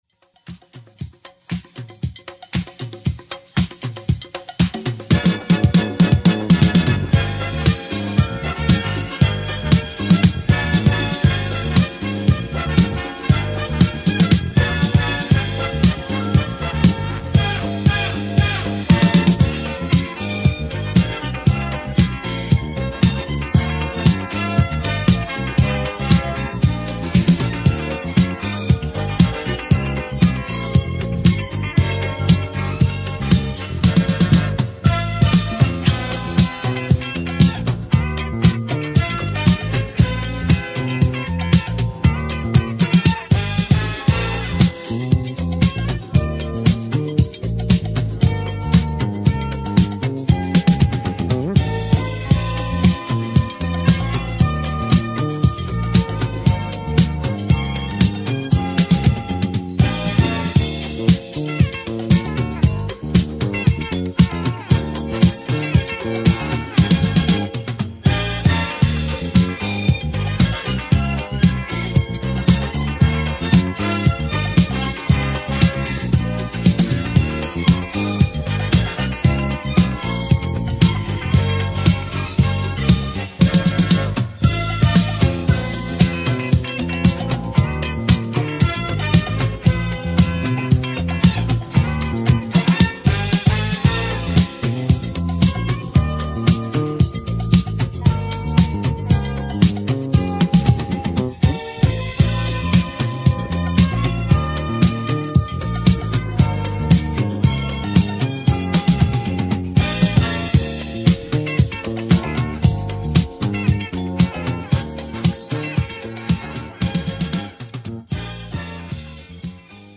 主に70sディスコ・ブギー路線のレア楽曲を捌いたエディット集となっています。
いずれもポジティヴなエネルギーで溢れた内容です！